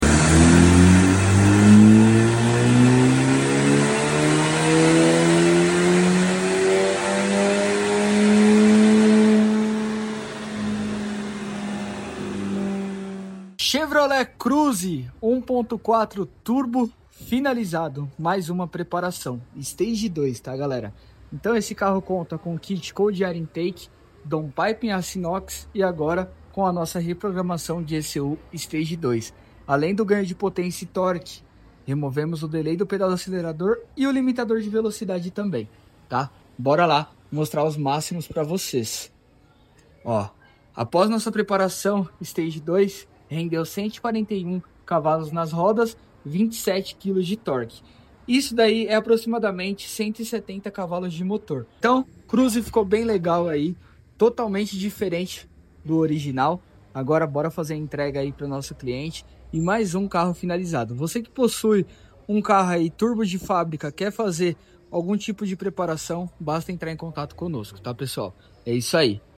🔥 Chevrolet Cruze 1.4 Turbo sound effects free download